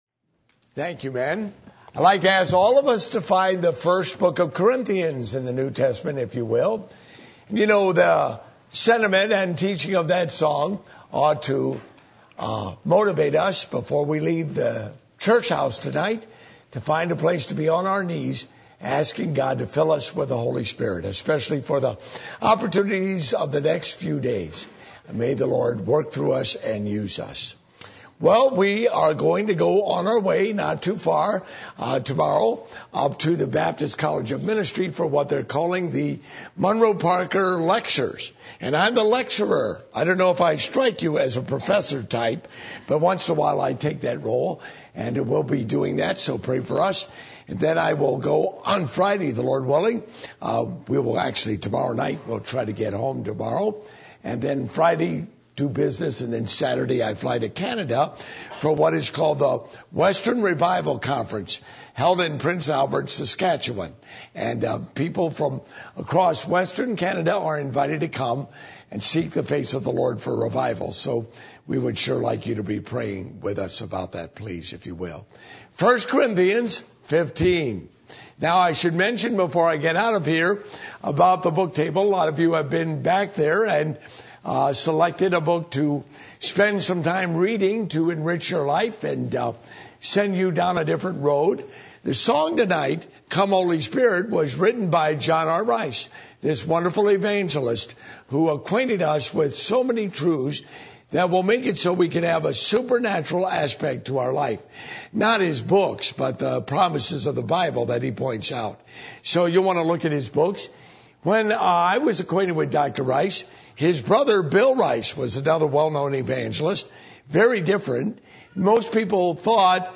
Passage: 1 Corinthians 15:51-58 Service Type: Revival Meetings